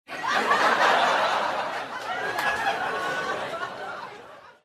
Звуки закадрового смеха